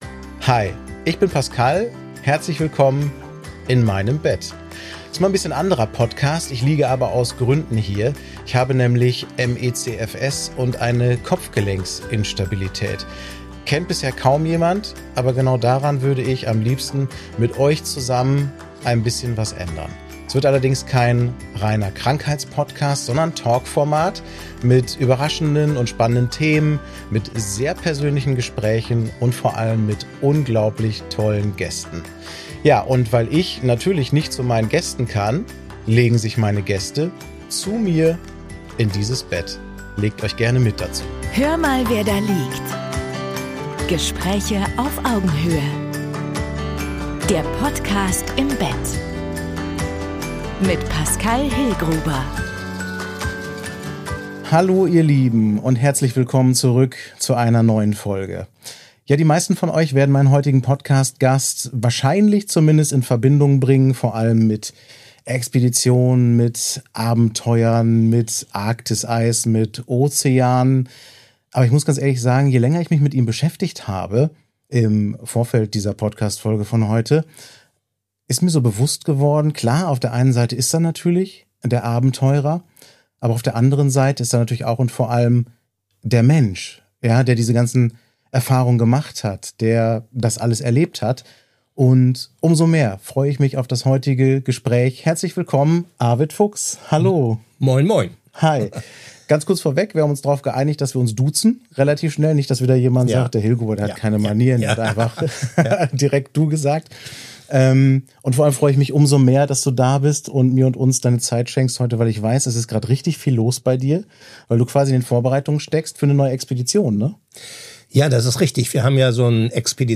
Beschreibung vor 6 Tagen Arved Fuchs ist Abenteurer, Expeditionsleiter, Autor und Umweltbeobachter – aber dieses Gespräch ist keine klassische Abenteuerfolge. In dieser Episode von „Hör mal, wer da liegt“ sprechen wir über über Demut gegenüber der Natur, über Neugier, Risiko, Verantwortung und darüber, was es mit einem Menschen macht, monatelang gemeinsam auf einem Schiff unterwegs zu sein. Außerdem geht es um ME/CFS, um die Sichtbarkeit schwerer Erkrankungen, um den in der Ostsee gestrandeten Buckelwal Timmy und um den Klimawandel, den Arved Fuchs durch seine jahrzehntelangen Expeditionen ganz unmittelbar beobachtet.